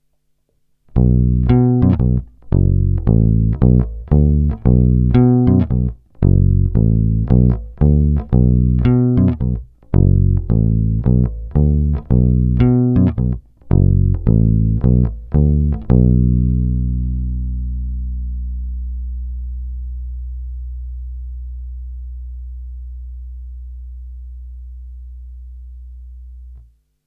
Zvuk asi mnohým sedět nebude, má výrazný kontrabasový charakter, kratší sustain, ale jinak je zamilováníhodný.
Není-li řečeno jinak, následující nahrávky jsou vyvedeny rovnou do zvukovky a kromě normalizace ponechány bez zásahů.
Hra nad snímačem – otevřeno